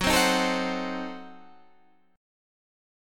F#dim7 chord